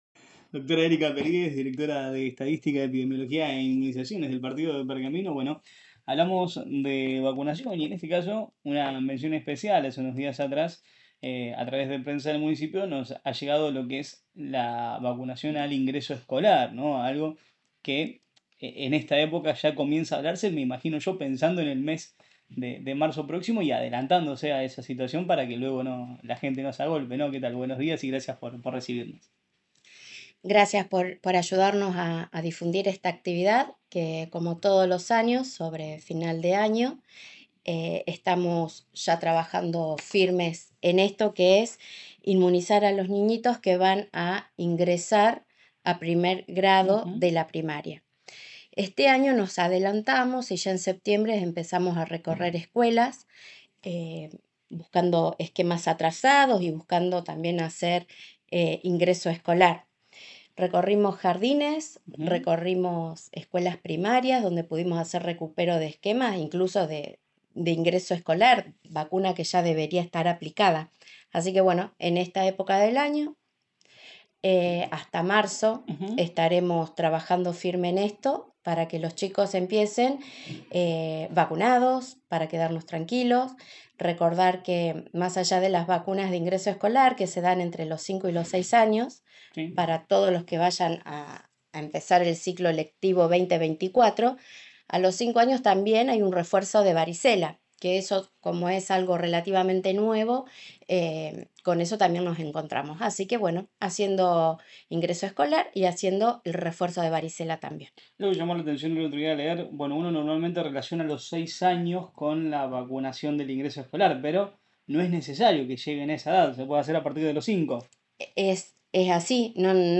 en diálogo con La Mañana de la Radio